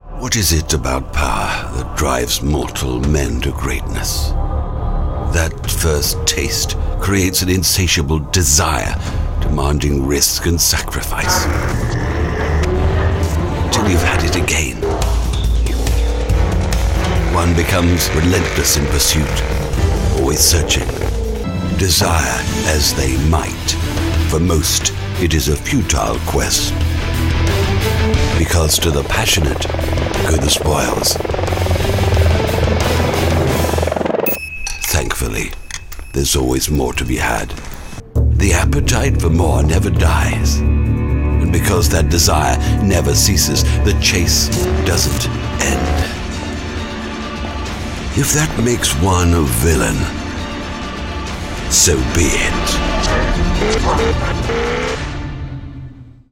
Native speakers
Engels (vk)